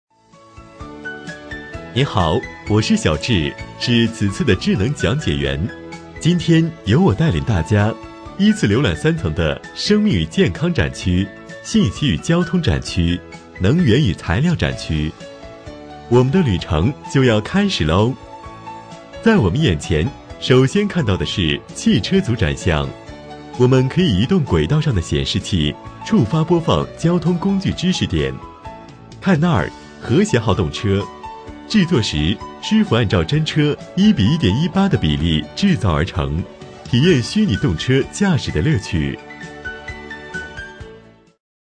【男31号课件】智能讲解员
【男31号课件】智能讲解员.mp3